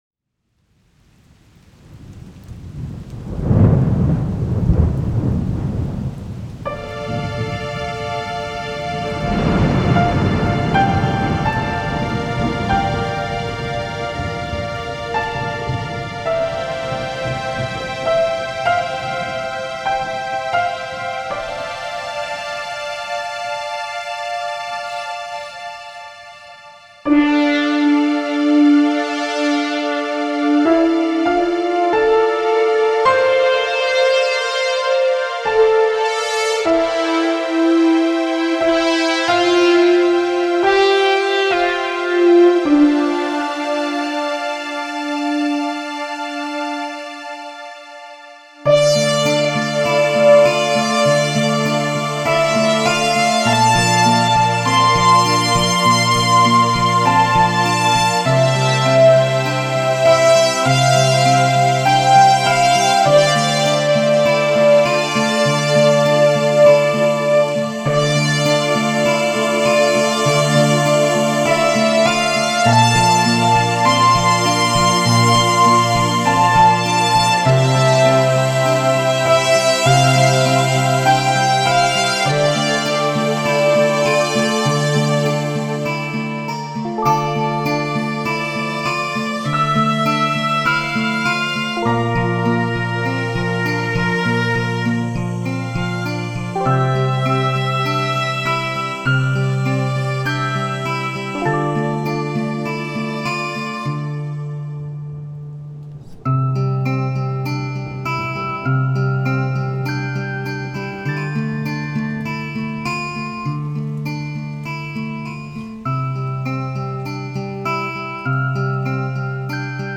Pretty simple, but it works. It's a remix.